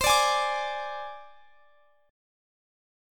Listen to Csus2#5 strummed